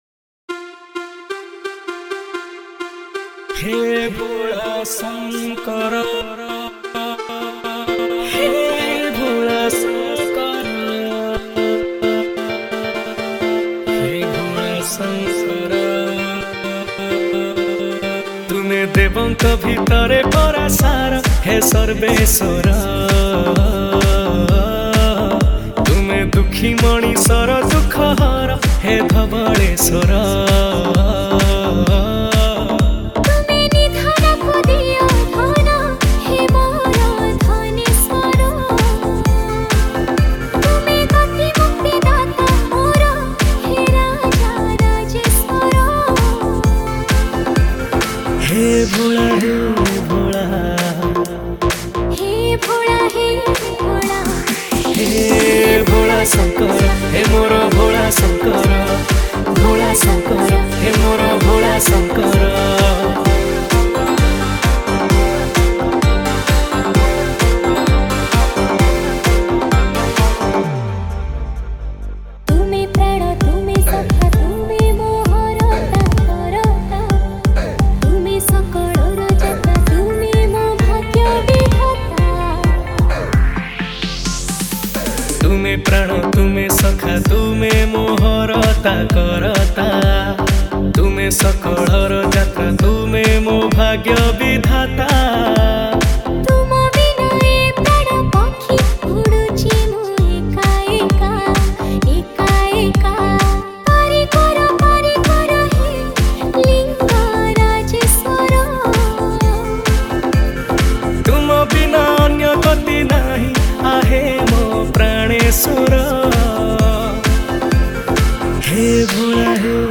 Category:  Odia Bhajan Dj 2025